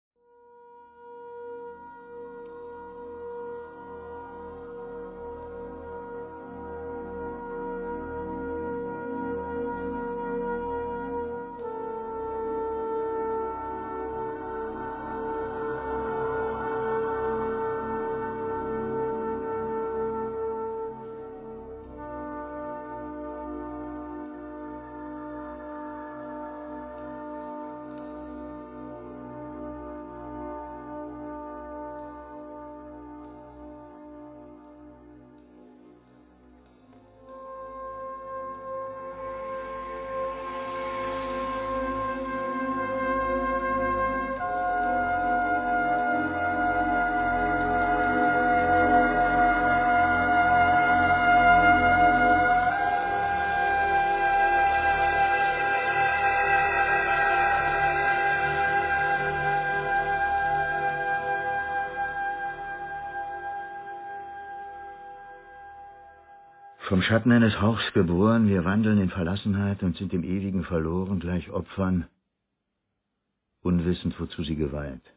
Klaus Lowitsch (Narrator)